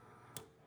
Alarms, Bell, Fire Alarm Buzzer Inside Box, Sanken 01 SND89446.wav